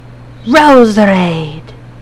infinitefusion-e18 / Audio / SE / Cries / ROSERADE.mp3
ROSERADE.mp3